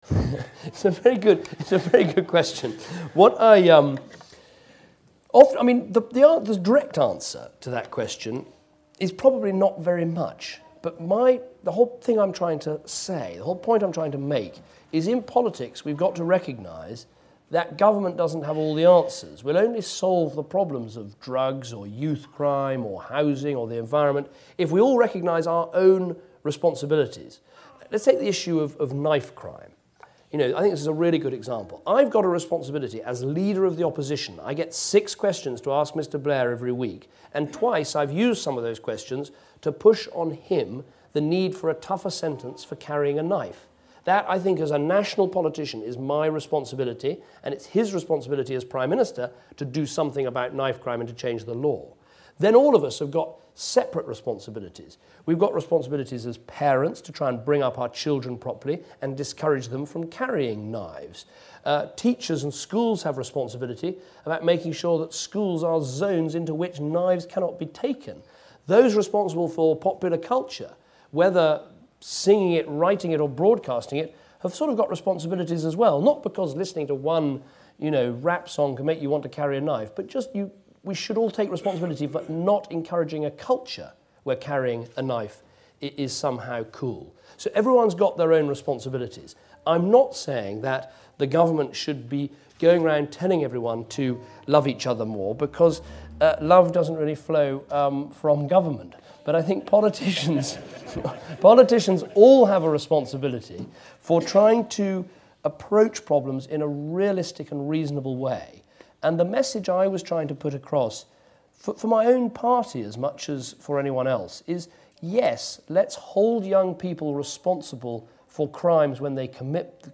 It was exactly that question he was asked in Balsall Heath in Birmingham on Friday after he had given a Chamberlain Lecture - his answer, in effect, was “not much”. Government it seems is well equipped to lock someone up but not to love them.